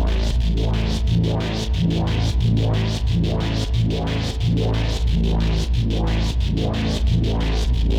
Index of /musicradar/dystopian-drone-samples/Tempo Loops/90bpm
DD_TempoDroneE_90-A.wav